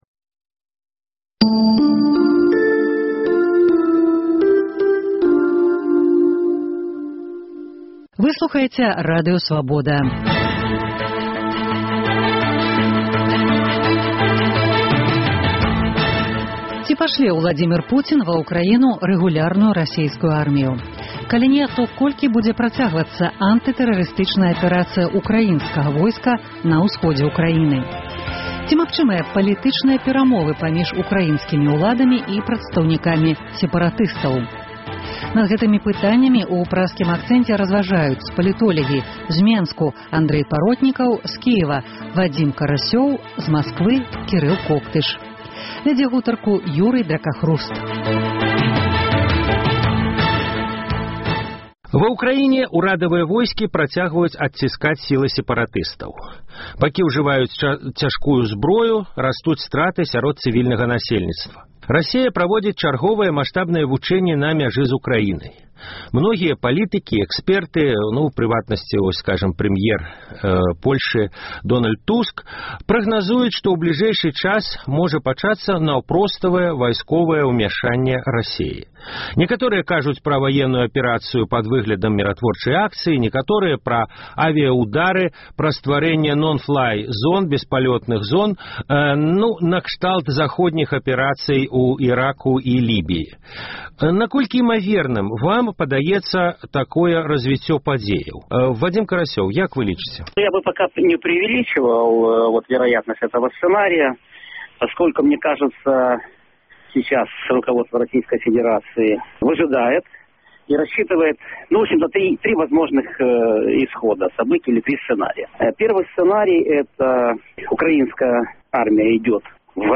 Ці магчымыя палітычныя перамовы паміж украінскімі ўладамі і прадстаўнікамі сэпаратыстаў? Над гэтымі пытаньнямі ў Праскім акцэнце разважаюць палітолягі